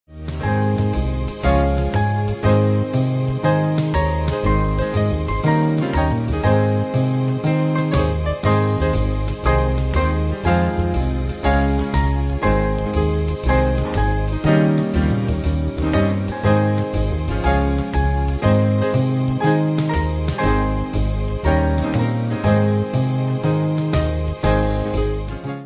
Band